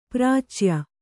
♪ prācya